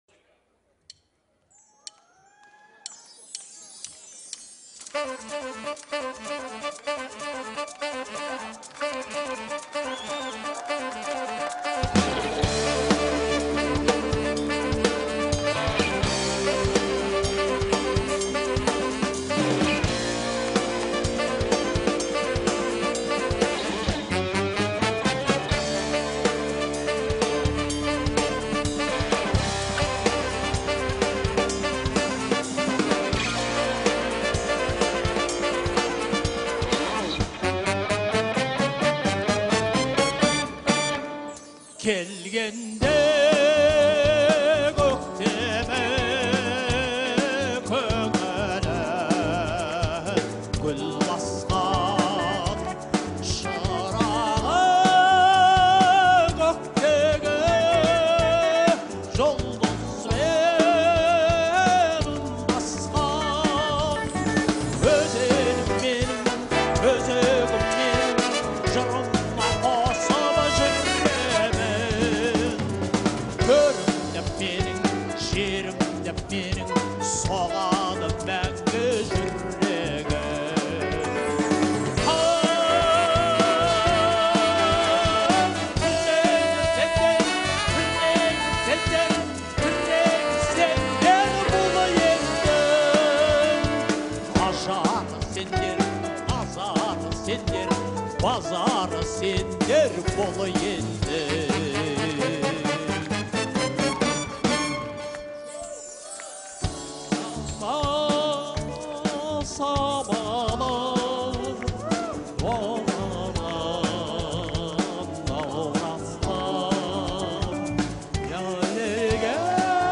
исполненная в жанре казахской народной музыки.